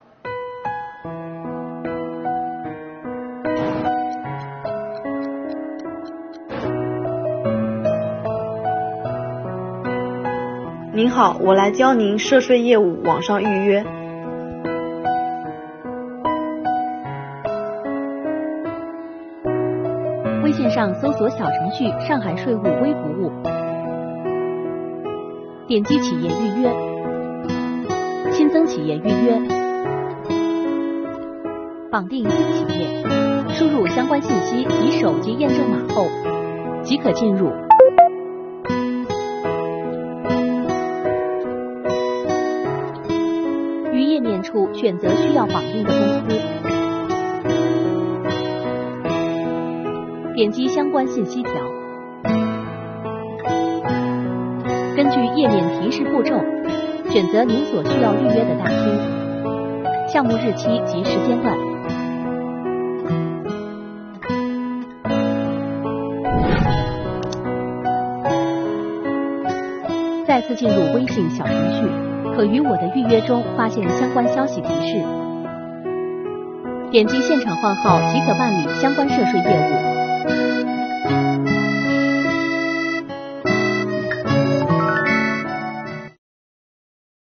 上海市税务局在全市范围内推行“全预约”办税，来看税务小姐姐教您如何预约办税，办税缴费“快人一步”。